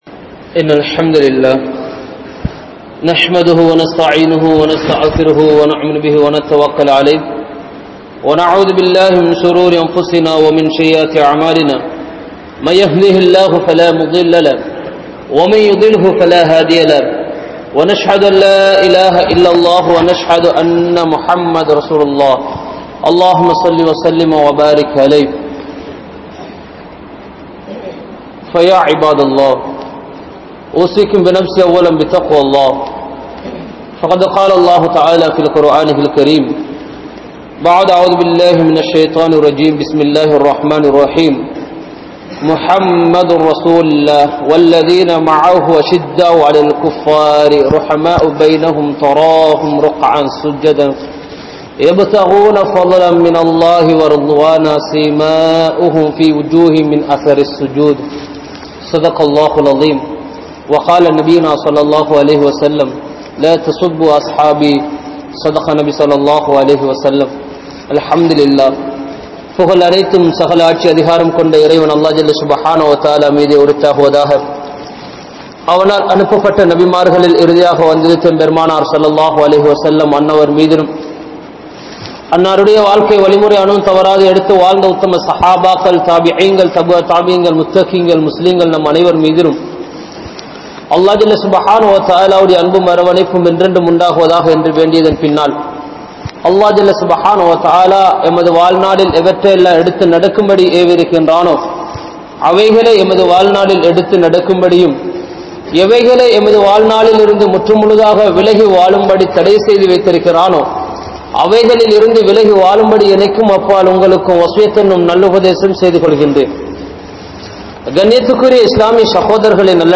Sahabaakkalin Thiyaaham (ஸஹாபாக்களின் தியாகம்) | Audio Bayans | All Ceylon Muslim Youth Community | Addalaichenai
PachchaPalli Jumua Masjidh